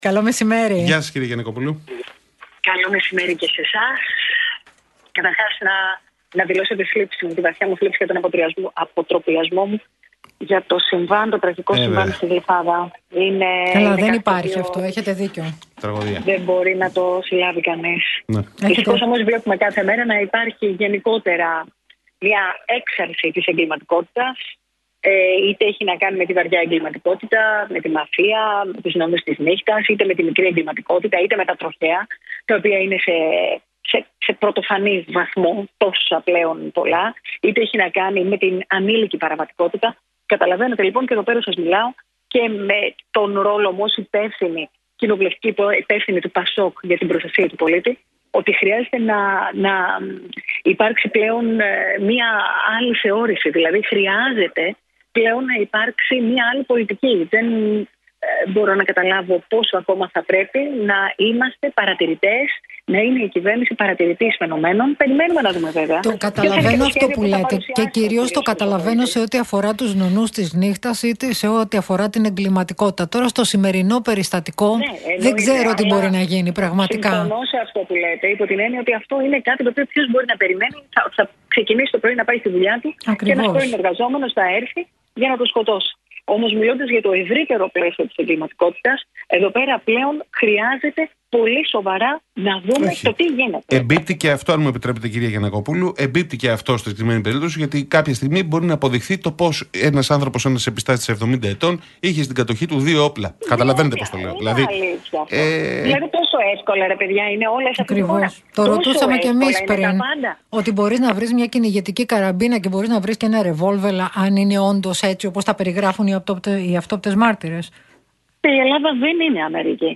Τις θέσεις του ΠΑΣΟΚ, σχετικά με την ψήφιση του νομοσχεδίου για τα μη κρατικά πανεπιστήμια, ανέλυσε η βουλευτής του κόμματος, Νάντια Γιαννακοπούλου στην εκπομπή